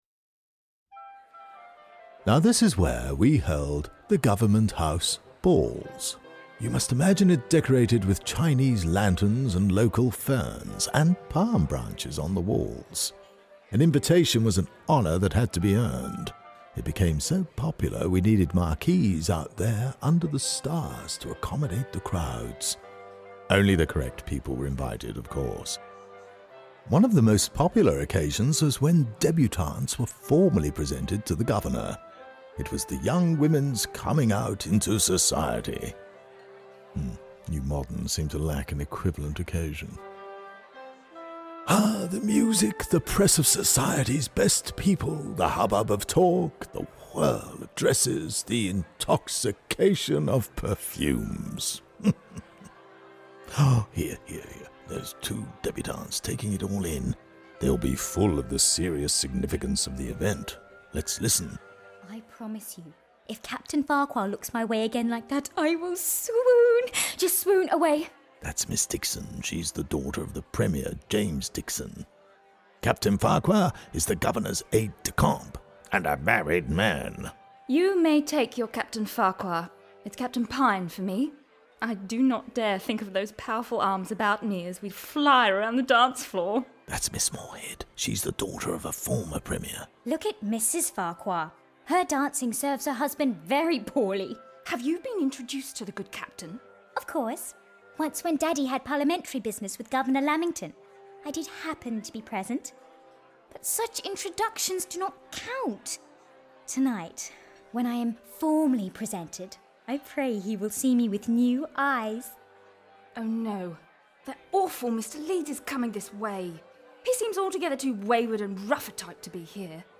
Led by the ghost of Governor Musgrave, who died in the House, the podcast tour guides you through key moments in the life of the House with audio dramatisations of exchanges that would have occurred between the characters of 19th century Queensland.